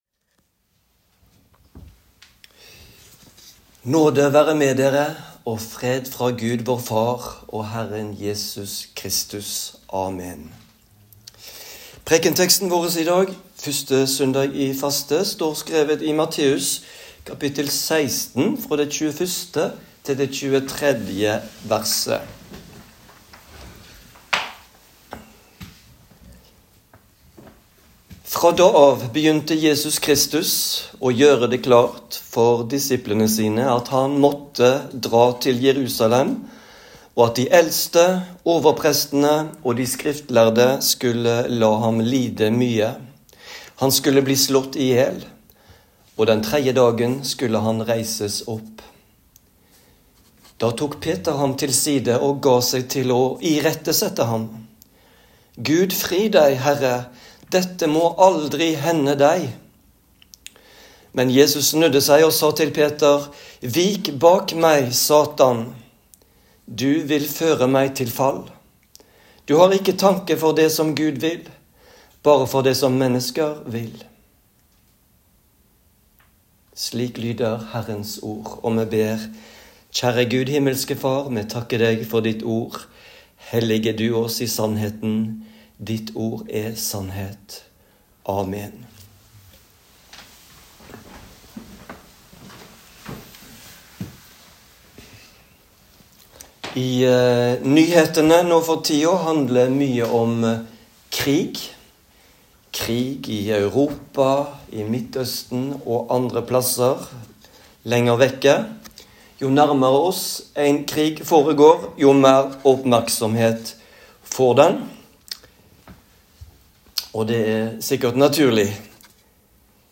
Preken på 1. søndag i faste